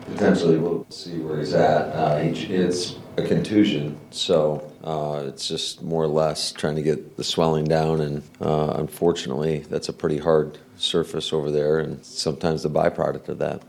(LEARFIELD) – There was some good news coming out of Packers coach Matt LaFleur’s meeting with the media on Monday.